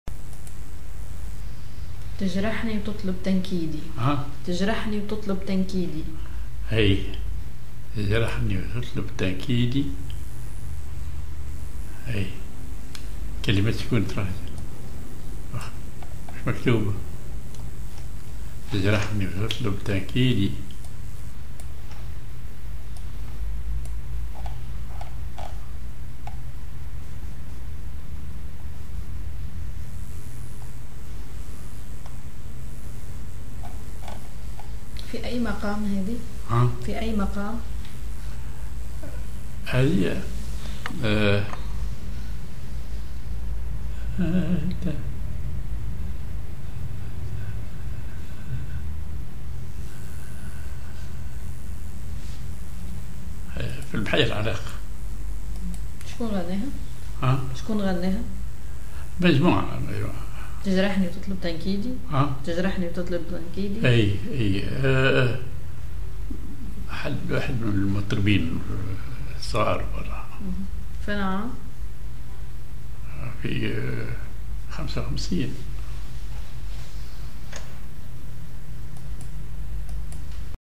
Maqam ar راست (أو محير عراق) على درجة الجهاركاه
genre أغنية